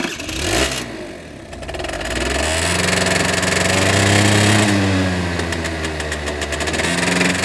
Index of /90_sSampleCDs/AKAI S6000 CD-ROM - Volume 6/Transportation/MOTORCYCLE
50-START -S.WAV